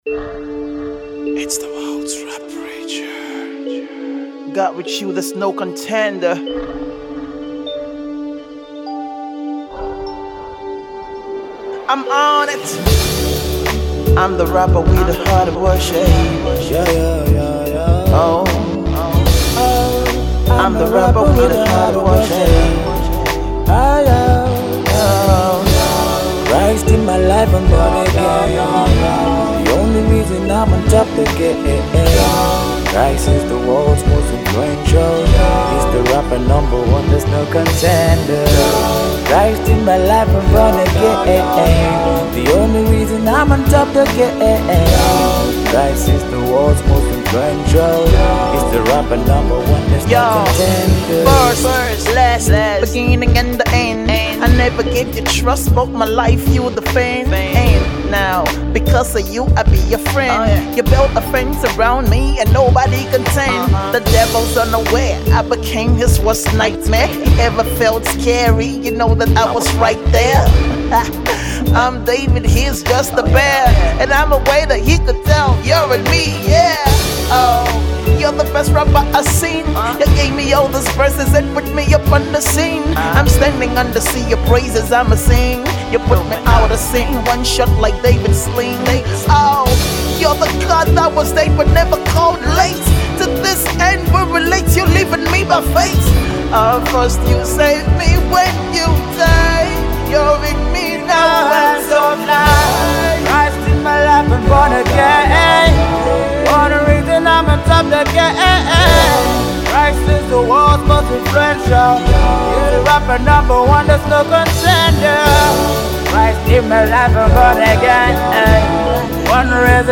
gospel tune